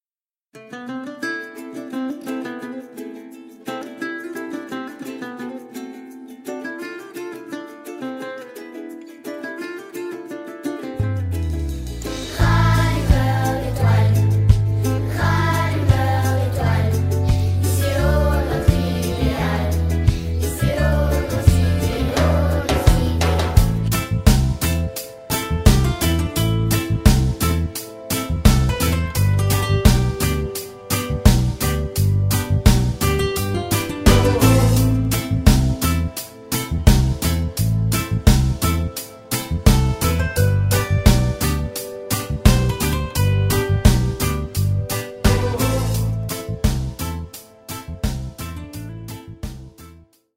avec choeurs